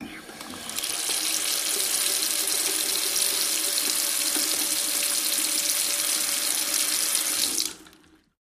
fo_sink_rundrainopen_01_hpx
Bathroom sink water runs with drain open and closed.